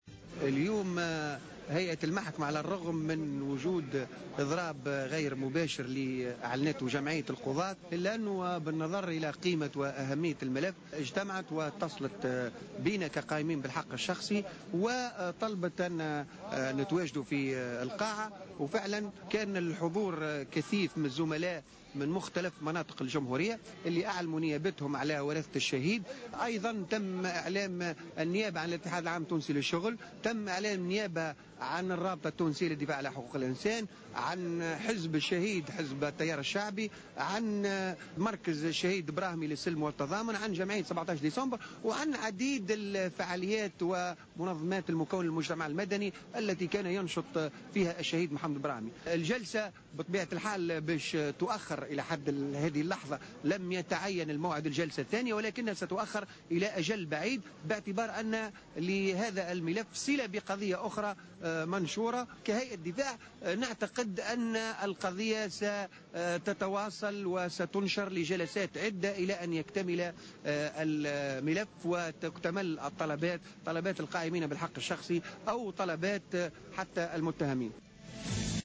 و أضاف في تصريح لمراسل "الجوهرة أف أم" أنه لم يتم تحديد موعد للجلسة المقبلة.